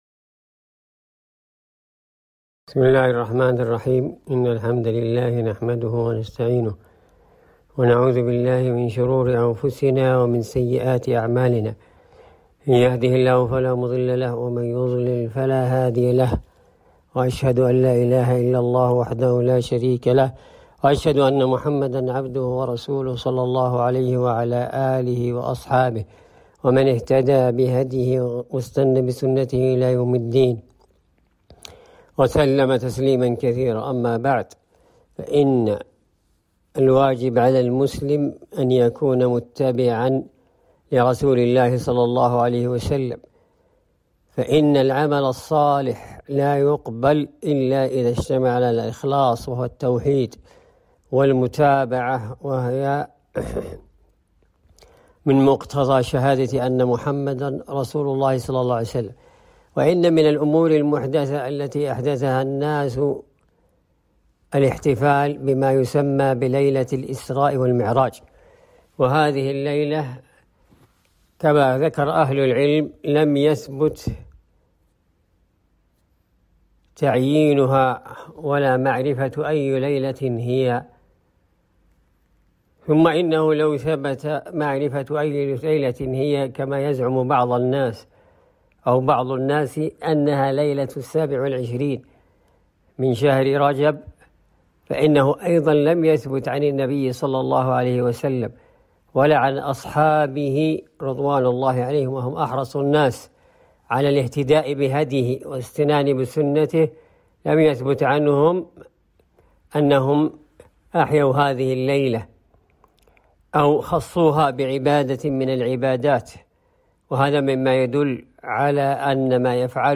كلمة - خطورة بدعة الاحتفال بليلة الاسراء والمعراج